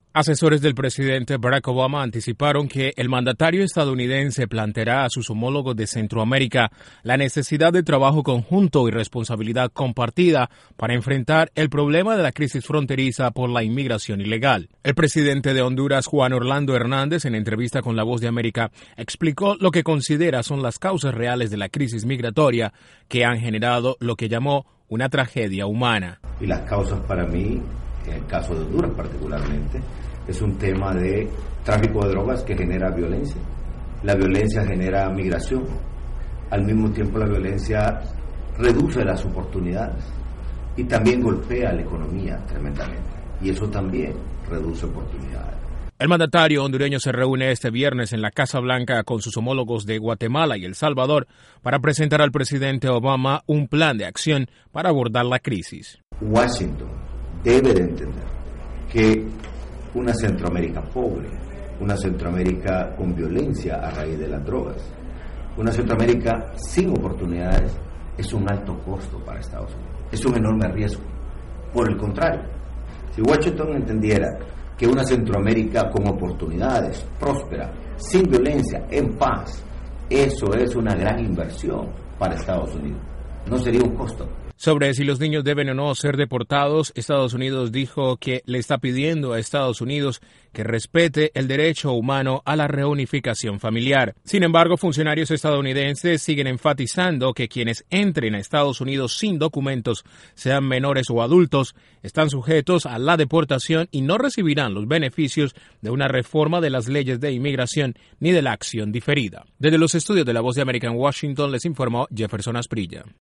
INTRO: Dentro de pocas horas iniciará el encuentro del presidente Obama con mandatarios de Centroamérica, en dialogo previo el presidente de Honduras dijo a la Voz de América que “Una Centroamérica pobre y sin oportunidades es un alto costo para EE.UU.”. Desde la Voz de América en Washington